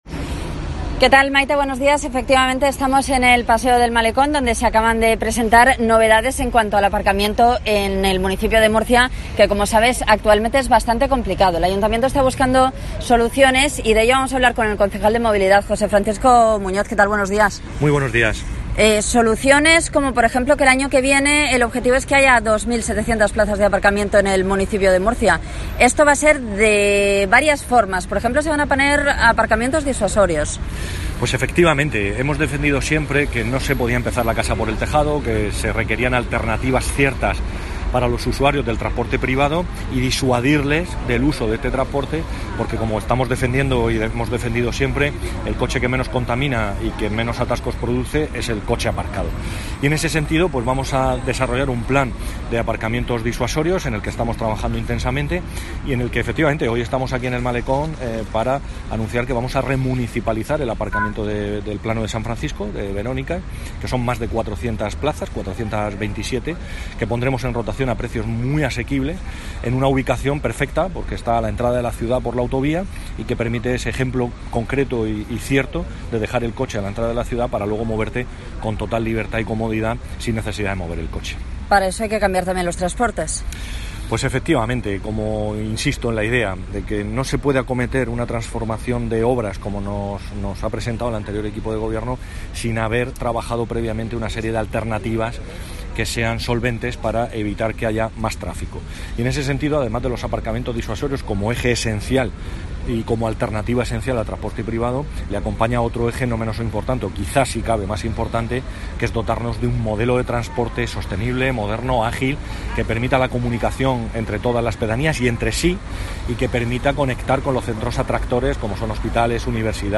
José Francisco Muñoz, concejal de Movilidad